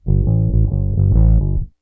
BASS 18.wav